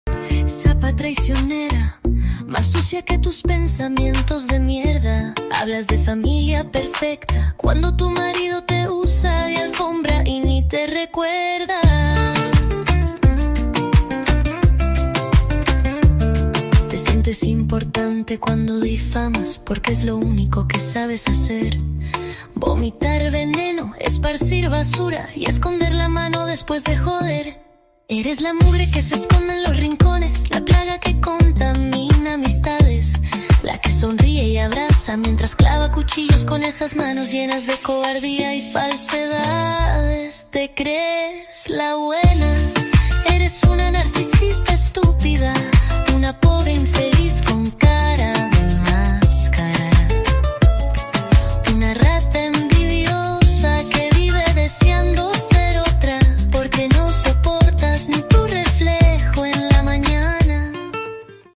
Música popular argentina